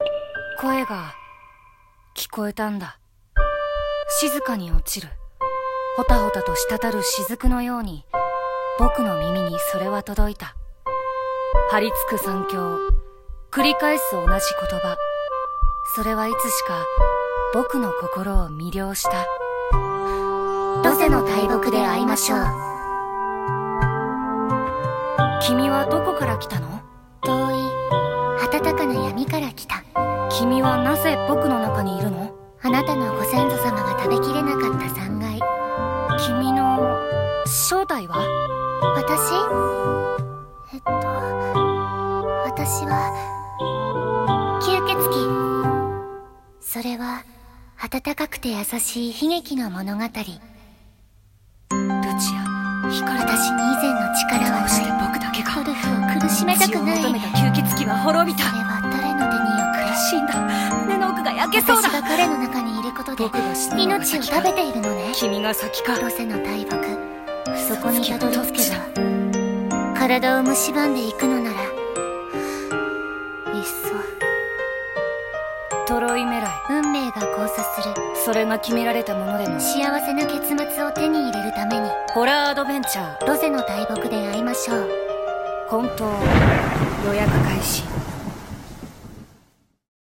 CM風声劇「ロゼの大木で逢いましょう」